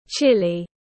Chilly /ˈtʃɪl.i/